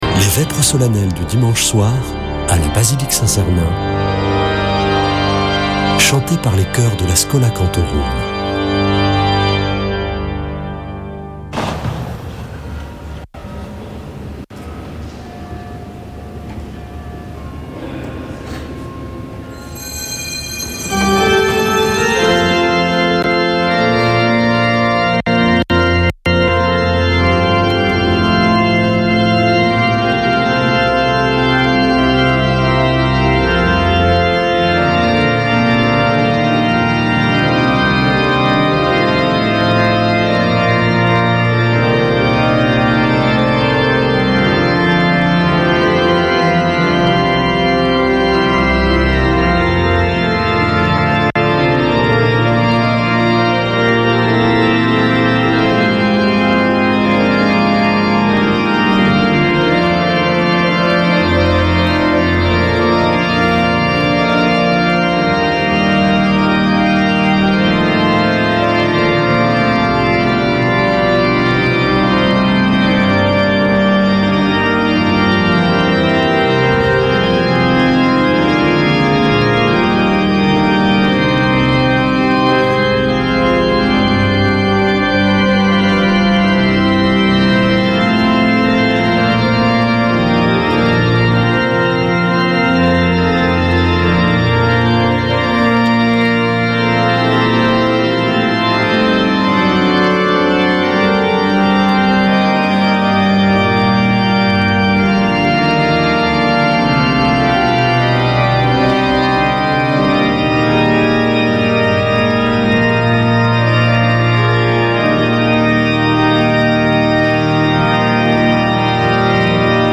Prière et Célébration
Une émission présentée par Schola Saint Sernin Chanteurs